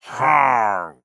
掠夺者：咕哝
空闲时随机播放这些音效
Minecraft_pillager_idle4.mp3